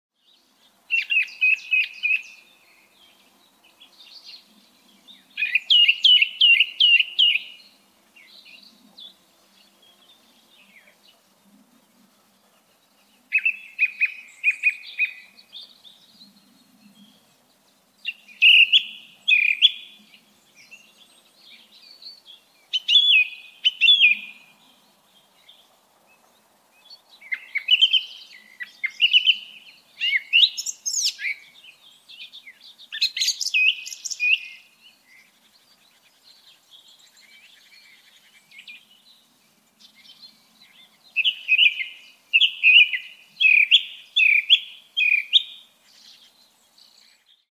Song thrush singing call Måltrost sound effects free download